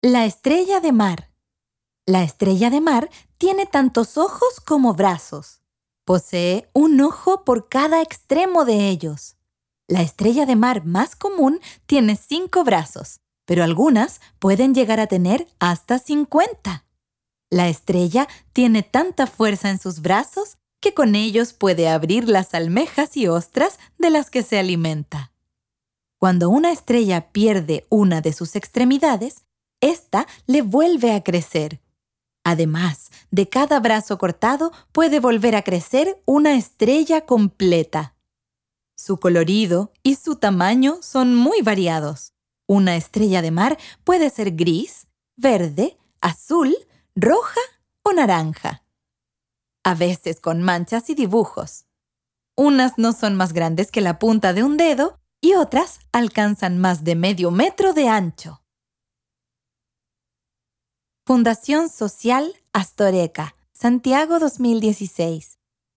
Audiocuento
Audiocuento informativo que relata las curiosidades de la estrella de mar, un fascinante organismo marino que, a pesar de su apariencia simple, posee habilidades sorprendentes, como la regeneración de brazos, entre muchas otras.